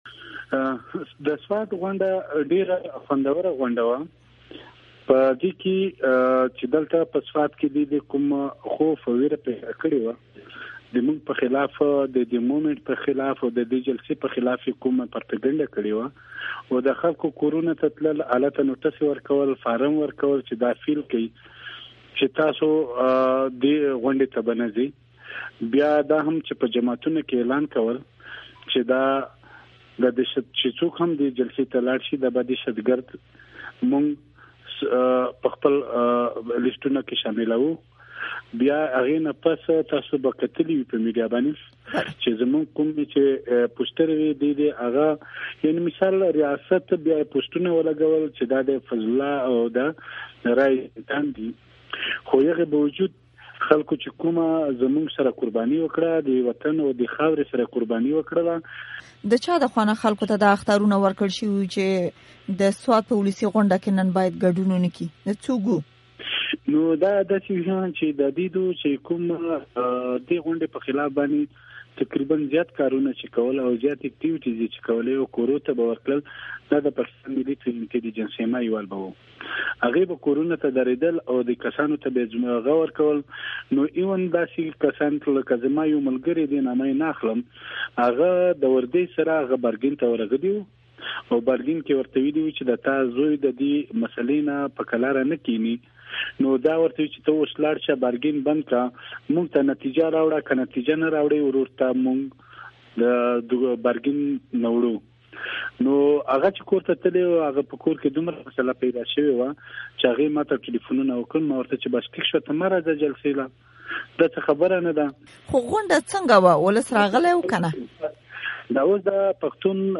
مرکې